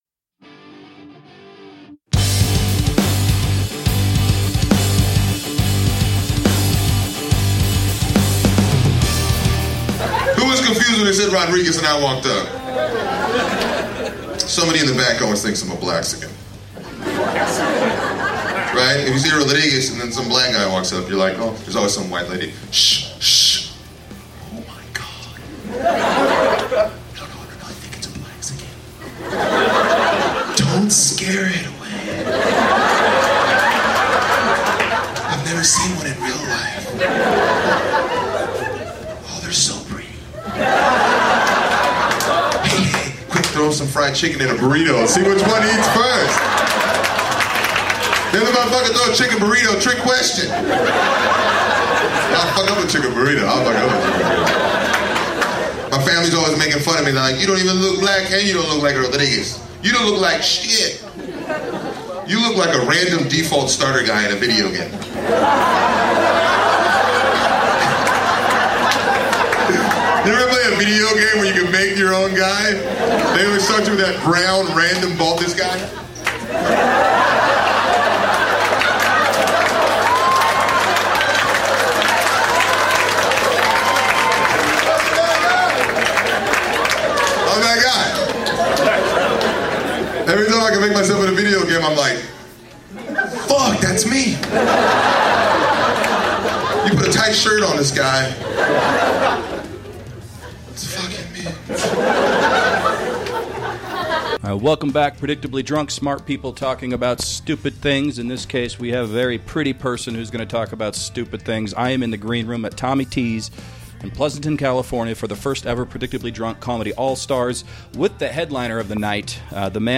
in the green room of Tommy T’s before the Predictably Drunk Comedy All-Stars